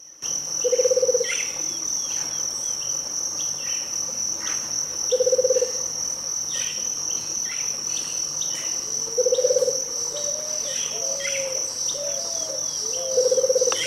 Rufous-capped Motmot (Baryphthengus ruficapillus)
Life Stage: Adult
Location or protected area: El Soberbio
Condition: Wild
Certainty: Photographed, Recorded vocal